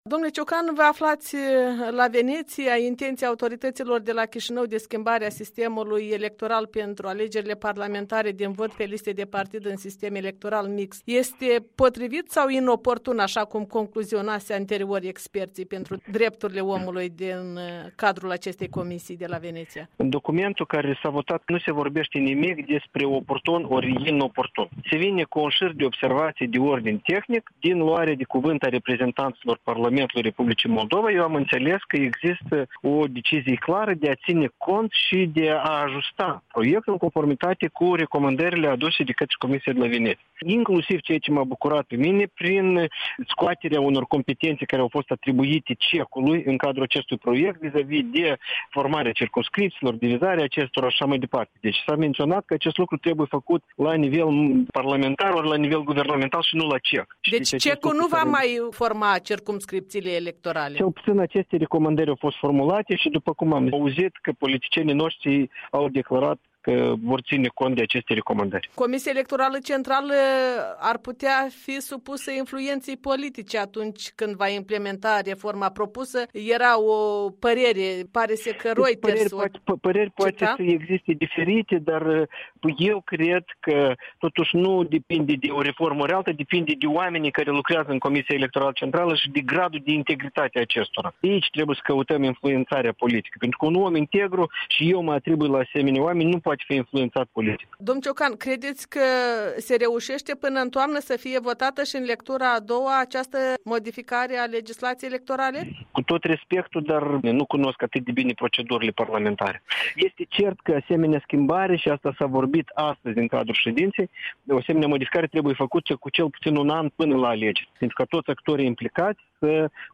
Interviu cu Iurie Ciocan